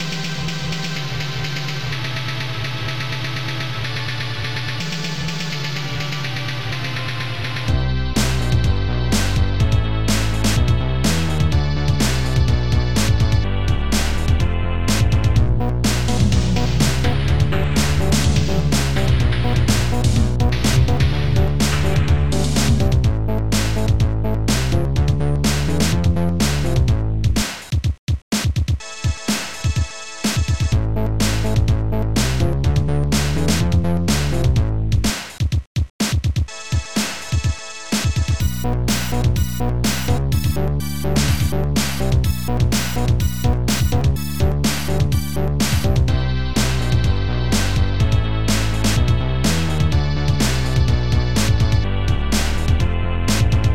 BABYBASSDM BABYSNARE2 LED-BASS1 LED-BASS2 LEDSTRIN-1